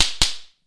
往復ビンタ２